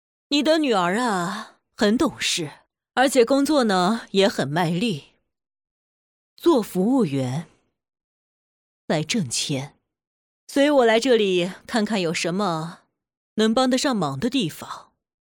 影视语音
班虹1班虹（年轻）： 约20岁，傲慢任性的富家小姐。音色年轻而富有活力，带有异国风情腔调，展现出独断和不可一世的性格特质。她的声音中透露出天生的优越感，表演充满自信与高傲，清晰地传达出角色性格。
班虹班虹（中年）： 约40岁，从富家千金成长为公司掌权人。她的音色成熟沉稳，但依然保留着年轻时的自信与傲慢。声音中透露出的是一种独断与威严，展现出岁月沉淀后的力量感。